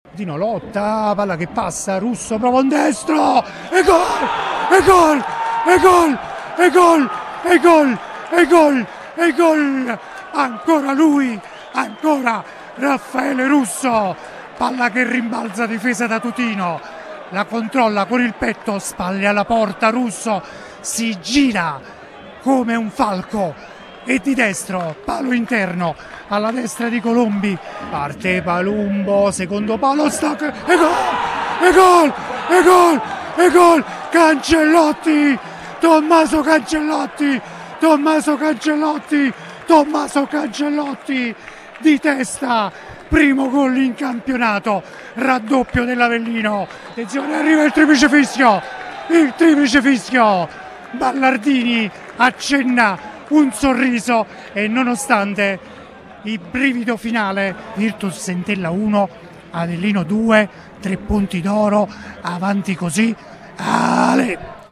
PODCAST | RUSSO E CANCELLOTTI DECIDONO ENTELLA-AVELLINO: RIASCOLTA L’ESULTANZA